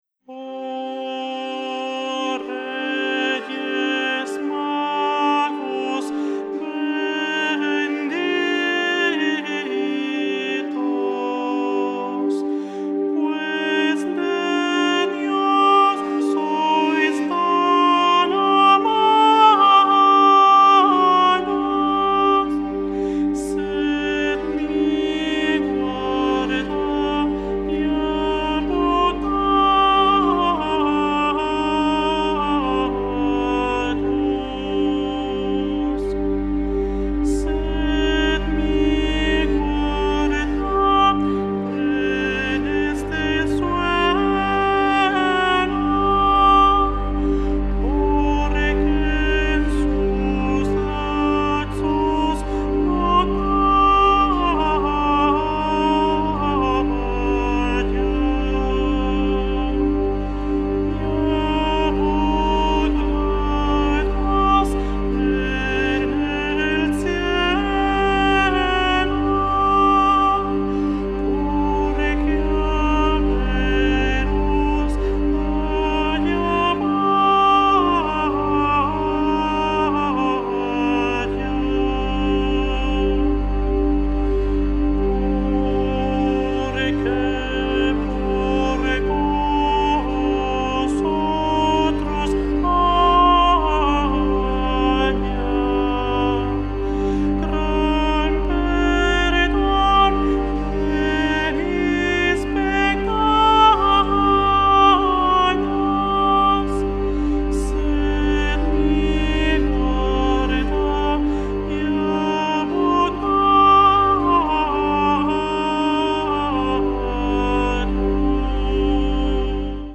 古楽×ドローン/エレクトロニクスな傑作です！